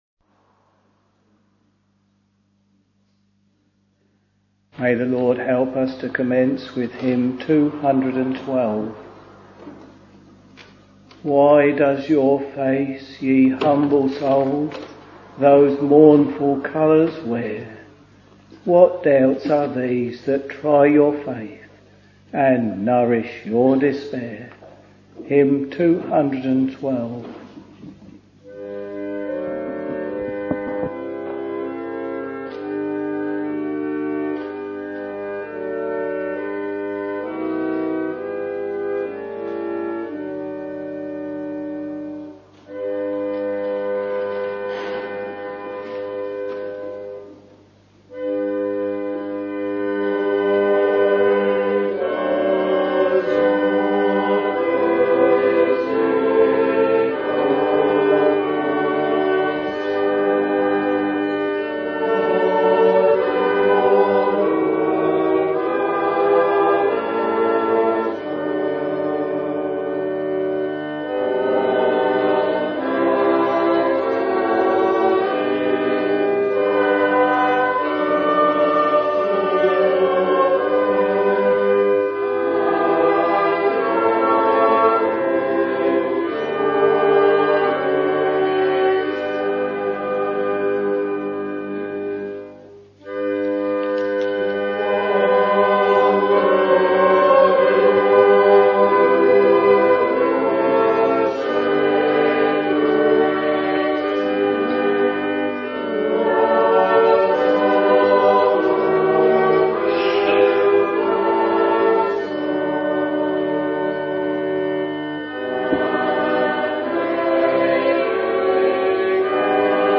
Week Evening Service